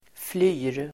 Uttal: [fly:r]